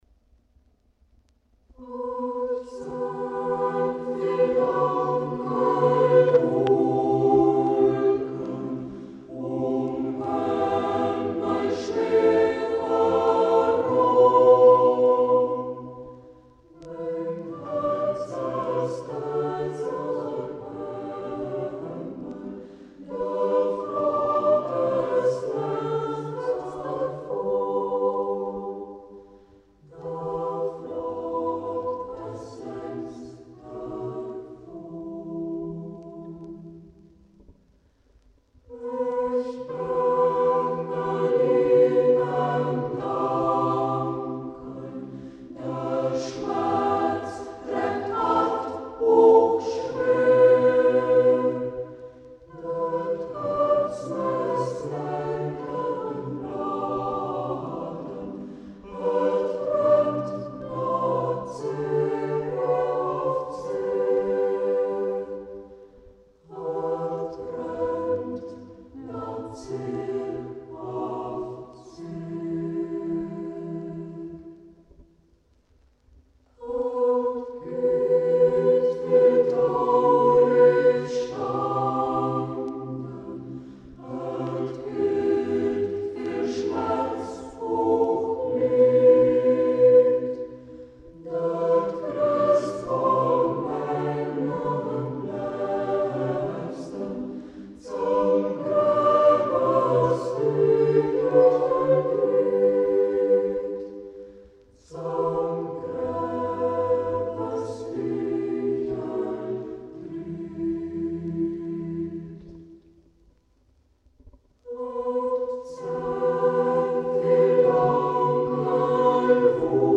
Cantores Cibinienses, Brukenthalgymnasium • 1988 • Ortsmundart: Hermannstadt • 2:34 Minuten • Herunterladen
Ortsmundart: Hermannstadt